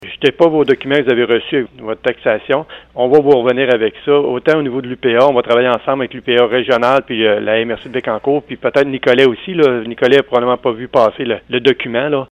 En entrevue au VIA 90,5 FM, il explique que les propriétaires pourraient craindre des représailles après avoir dénoncé des activités criminelles à la police.